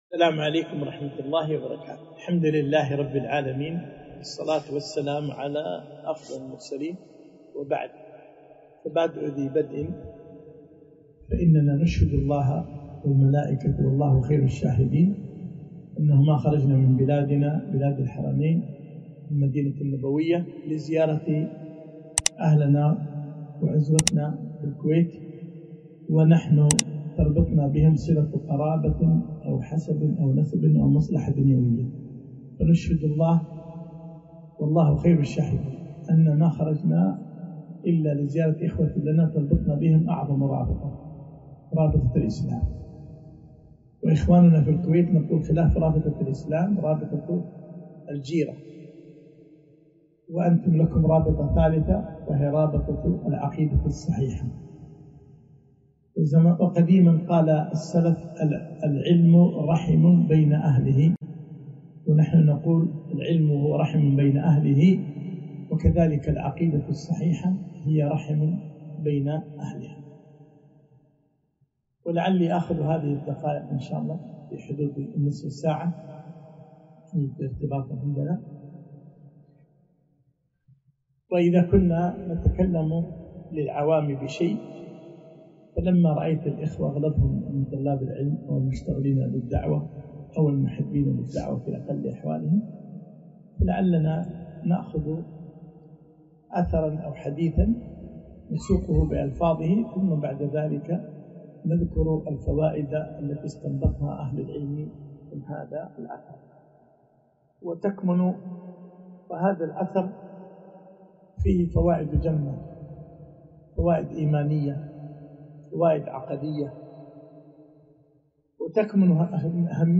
كلمة - اتبعوا ولا تبتدعوا